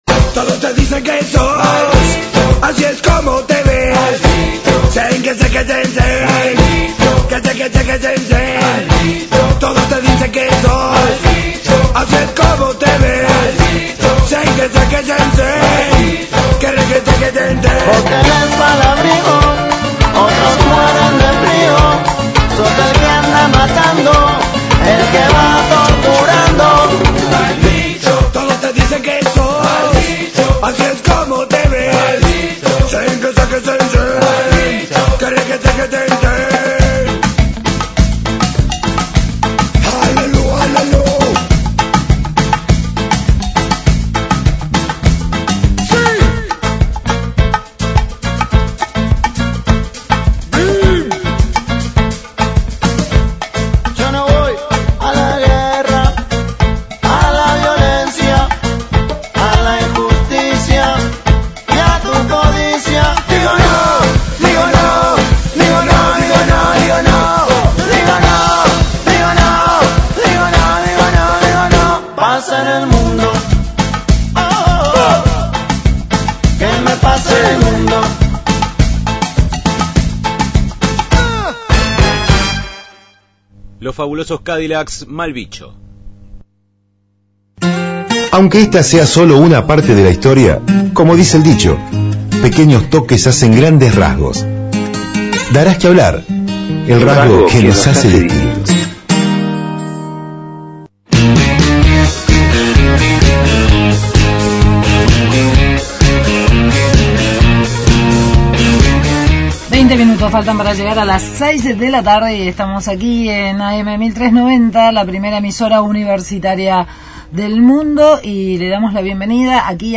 Entrevista al diputado prov. Rubén Eslaiman sobre seguridad – Radio Universidad